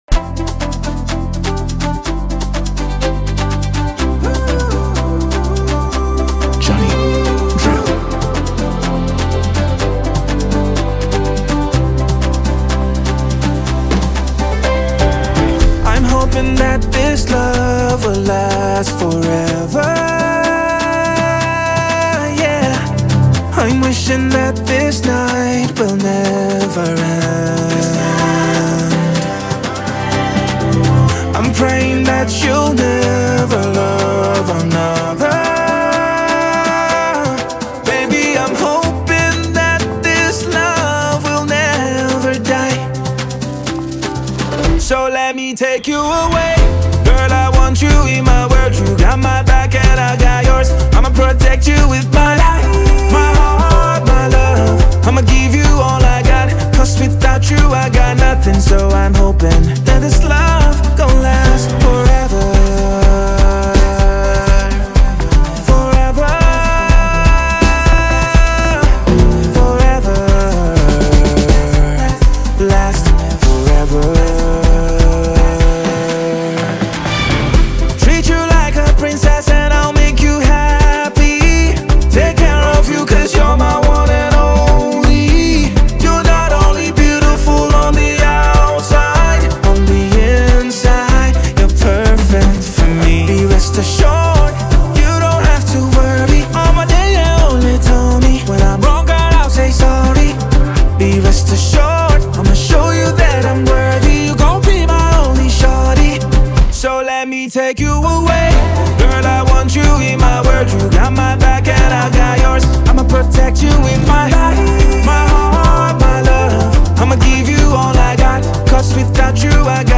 The arrangement is minimal yet powerful